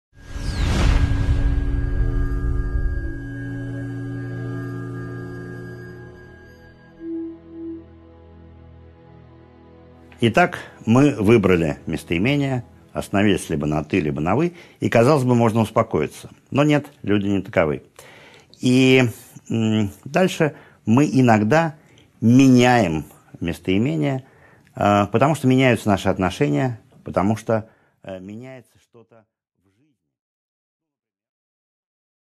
Аудиокнига 6.5 Смена местоимения | Библиотека аудиокниг